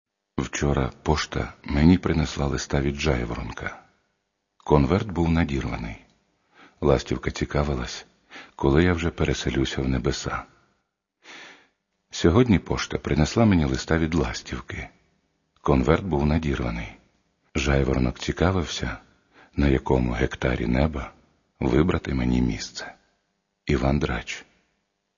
Catalogue -> Other -> Bards